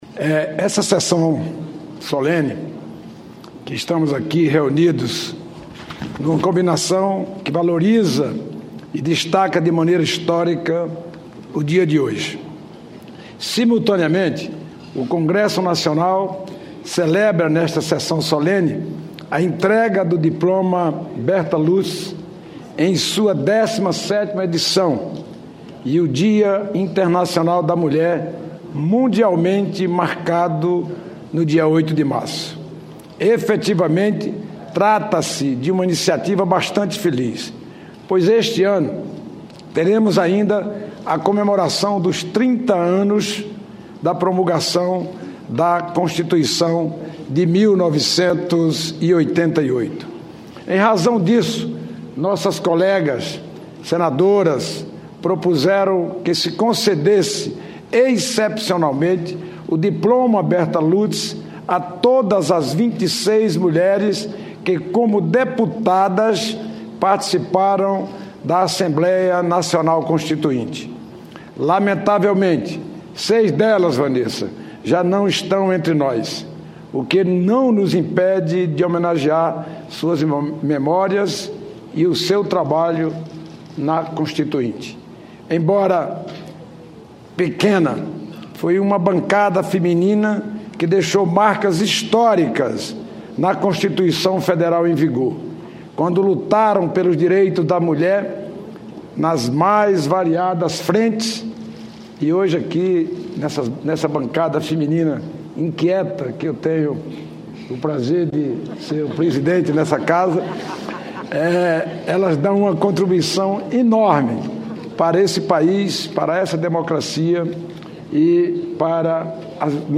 Sessão Solene para entrega do Diploma Bertha Lutz a mulheres constituintes
Pronunciamento do senador Eunício Oliveira, presidente do Senado Federal